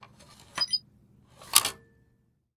Звуки турникета
Звук карты у турникета открыл доступ